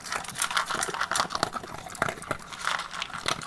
peteat.ogg